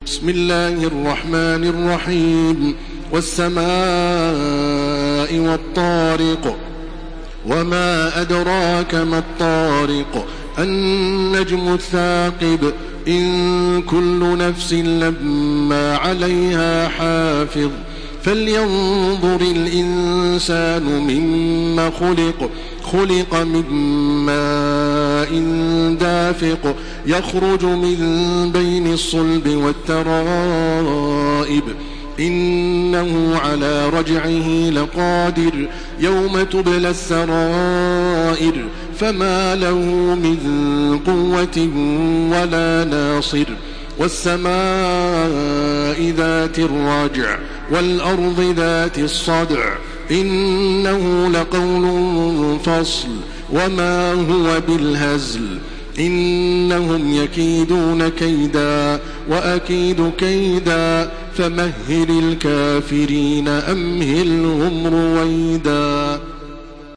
Download Surah At-Tariq by Makkah Taraweeh 1429
Murattal